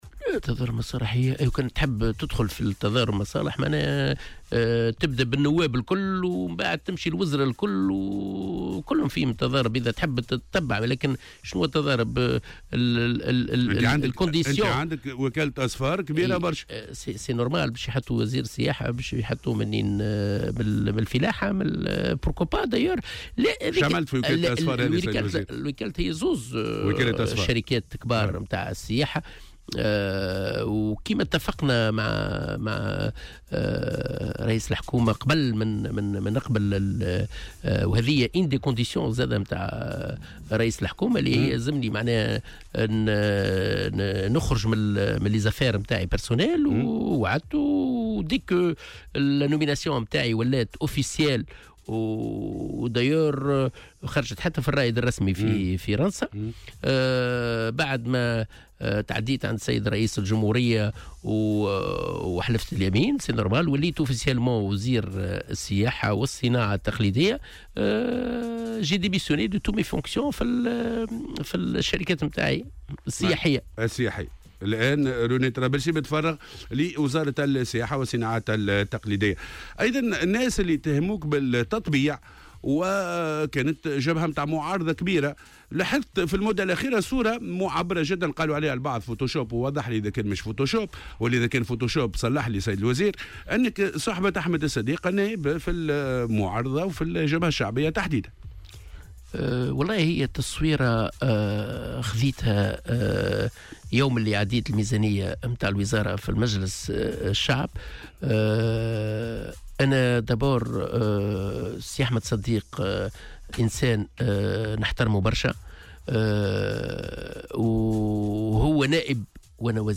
وأوضح في حوار اليوم الأربعاء مع "الجوهرة أف أم" أنه من الطبيعي أن يكون وزير السياحة من أهل القطاع ومُلمّ بمشاغله، مشيرا إلى أنه اتفق مع رئيس الحكومة قبل قبوله بالمنصب على ضرورة التخلي عن مشاريعه الخاصة وأن يكون متفرّغا.